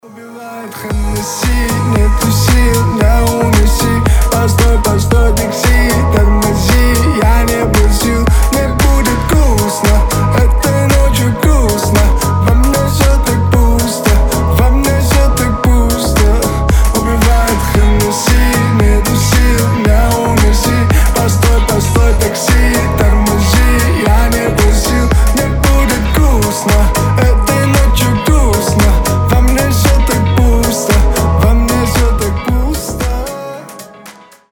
• Качество: 320, Stereo
мужской голос
грустные